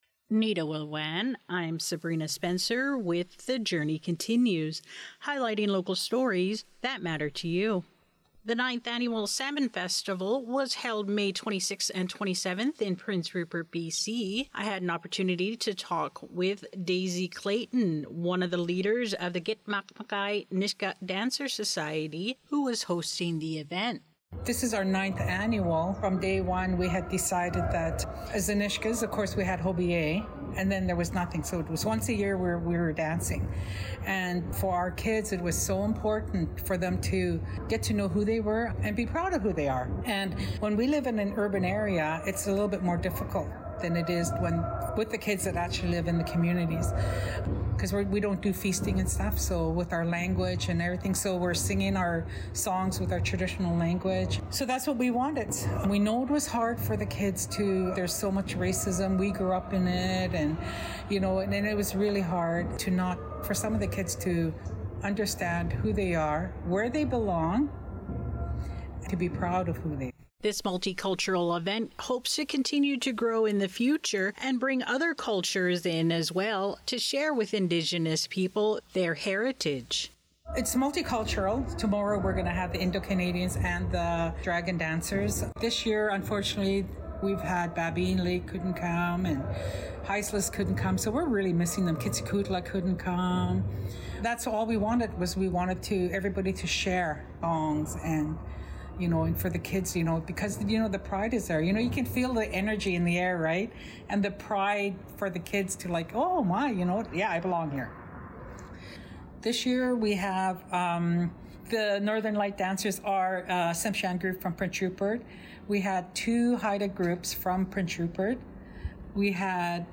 CFNR - Terrace • BC